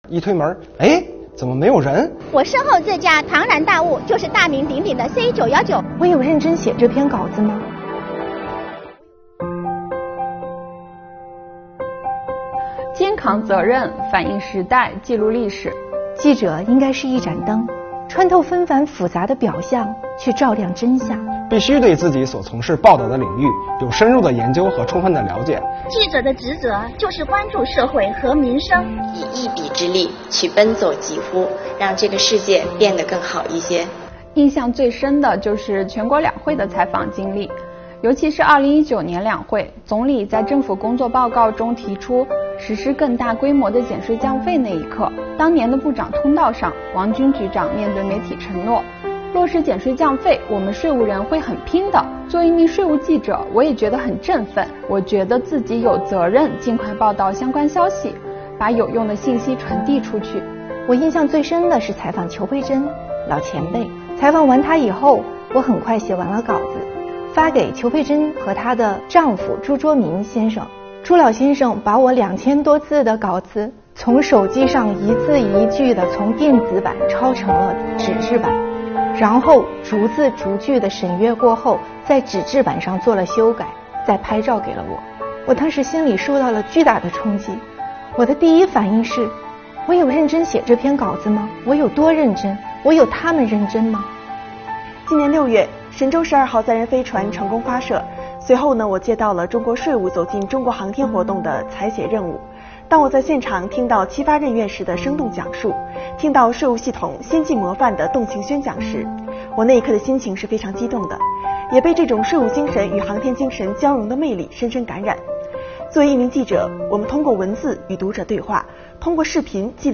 让我们看看奋斗在新闻采访一线的税务记者，听听他们的故事。